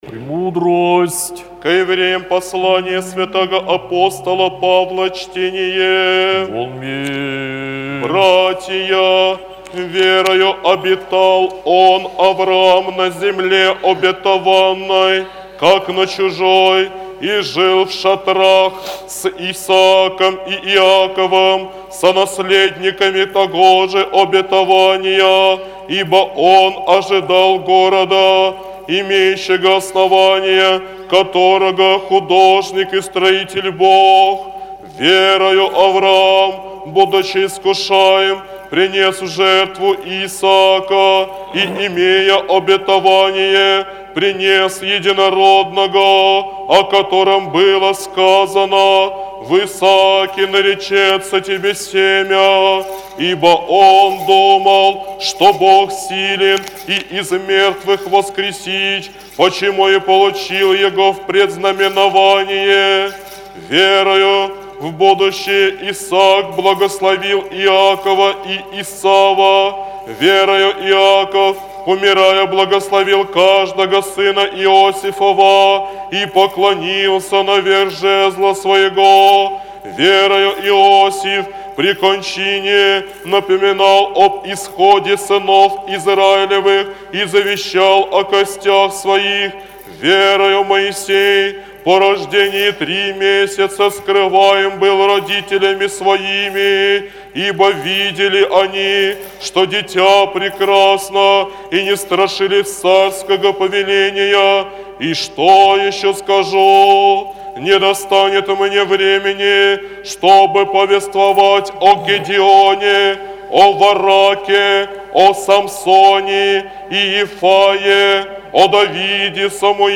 Апостольское чтение Аудио запись